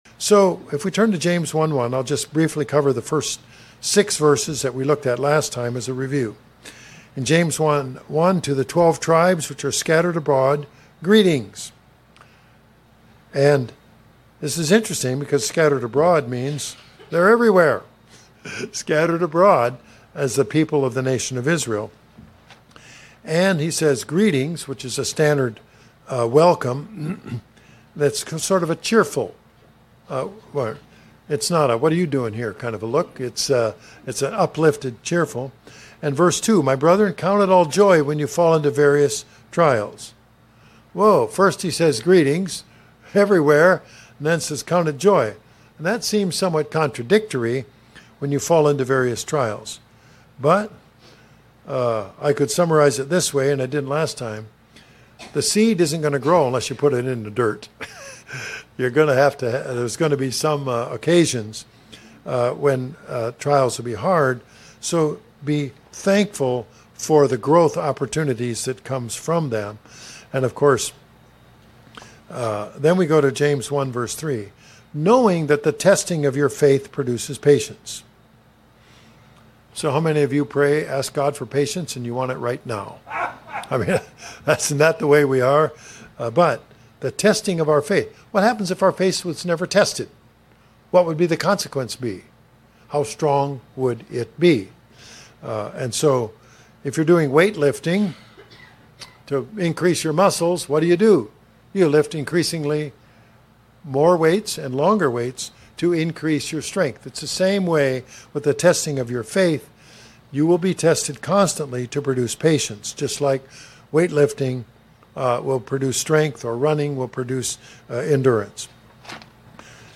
Bible Study James 1:7-11
Given in Springfield, MO